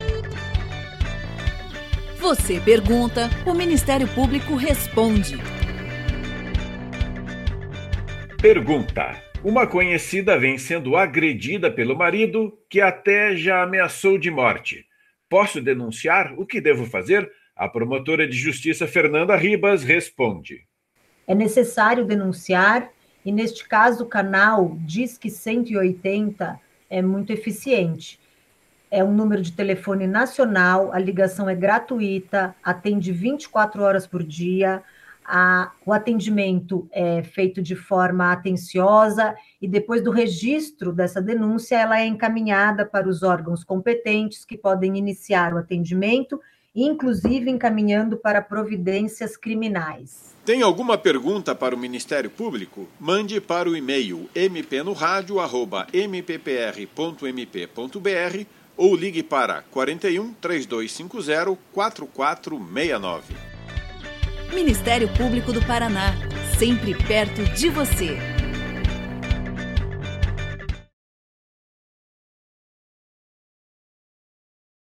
Você pergunta, o MP responde. Em áudios curtos, de até um minuto, procuradores e promotores de Justiça esclarecem dúvidas da população sobre questões relacionadas às áreas de atuação do Ministério Público.
Ouça resposta da promotora de Justiça Fernanda Campanha Motta Ribas